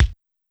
TEC Kick.wav